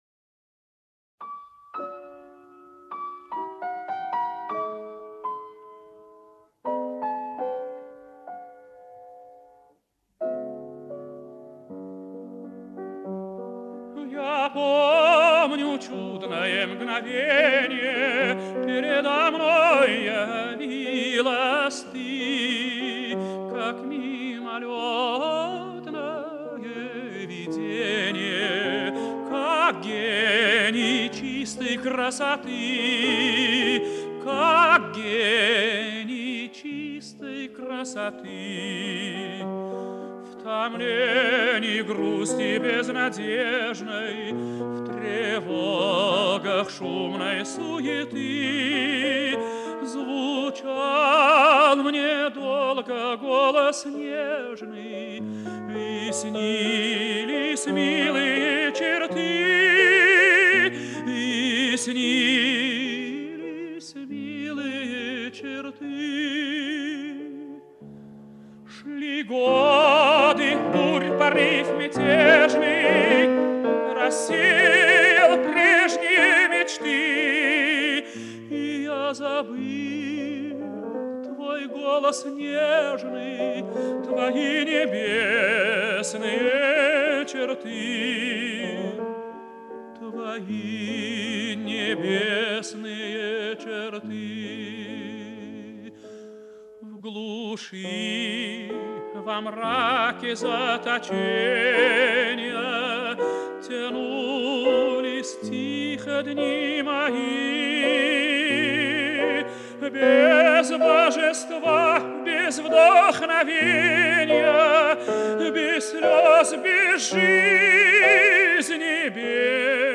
файл) 6,49 Мб Романс М.И. Глинки на слова А.С. Пушкина "Я помню чудное мгновенье". 1